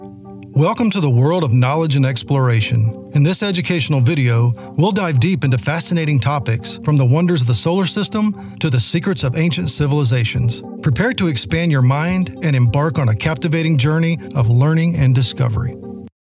Educational Video Narration